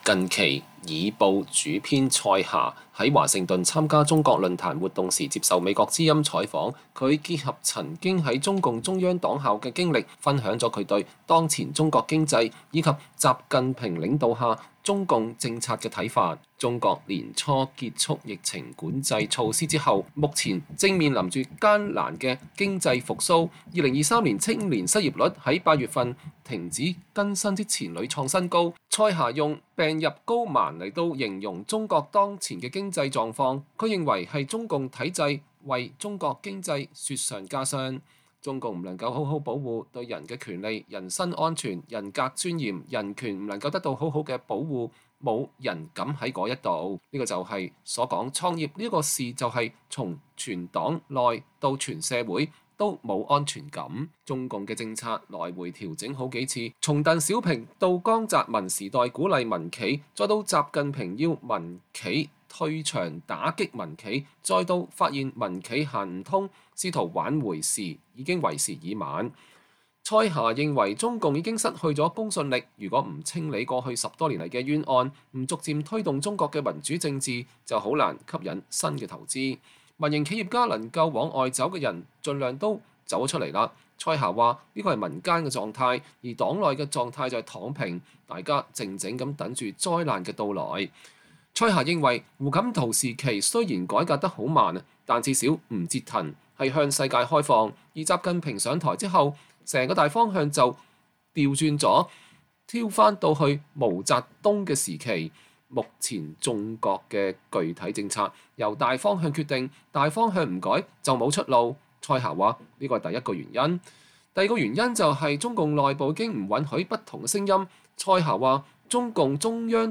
近期《議報》主編蔡霞在華盛頓參加“中國論壇”活動時接受美國之音採訪，她結合曾經在中共中央黨校的經歷，分享了她對當前中國經濟，以及習近平領導下中共政策的看法。